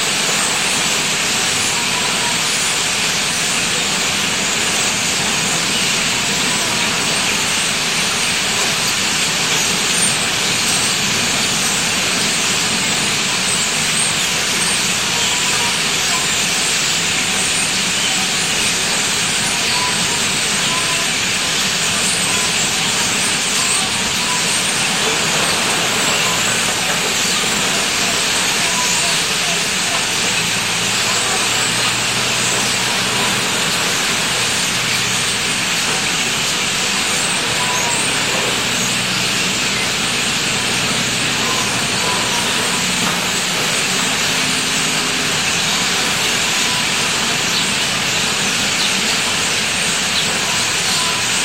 Another thing about starlings is the noises they make. Not exactly a song, in many ways more expressive, filled not only with notes and whistles, but also pops, crackles and other warblings I don’t even know how to describe.
But as soon as they settle the twittering begins so that, if you’re standing on the pier, you’ll suddenly find the air filled with a great mass of shrill calls and squeakings that I’d be tempted to describe as a roar if it wasn’t so high pitched; louder than the waves, and the cocktail jazz and 80’s soul that seems to be the preferred piped music of the pier managers.
Today, as soon as their display ended I headed for the pier to record this extraordinary sound, which I present here, along with today’s photographs:
starlings-brighton-pier-jan-2016.mp3